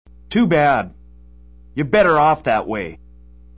If you thought Resident Evil was the apex of bad voice acting, think again.